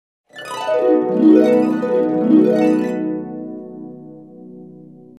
Звуки волшебной палочки
Мелодии магии и волшебства из сказок